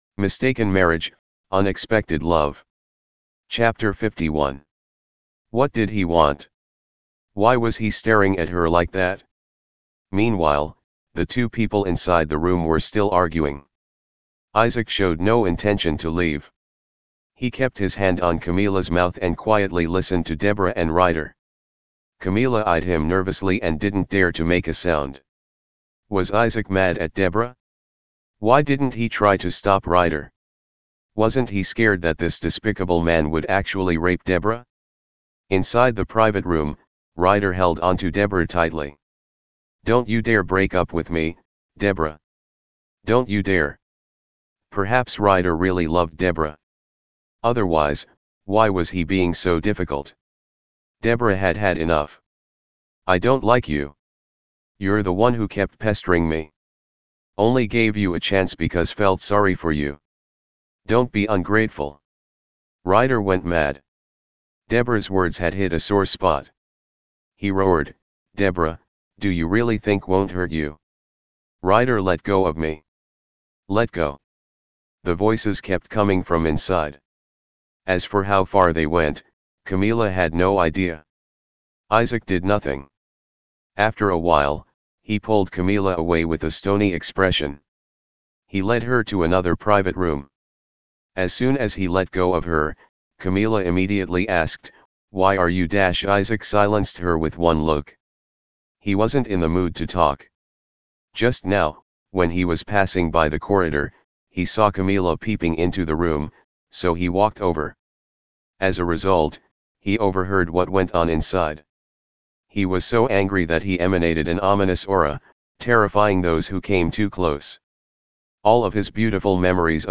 Mistaken Marriage, Unexpected Love Audiobook and PDF version Chapter 51 to 60